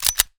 gun_pistol_cock_04.wav